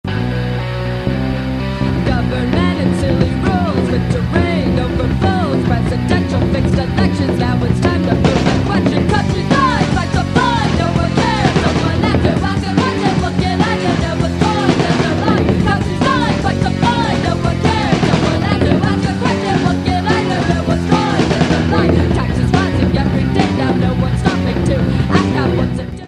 (punk), 1997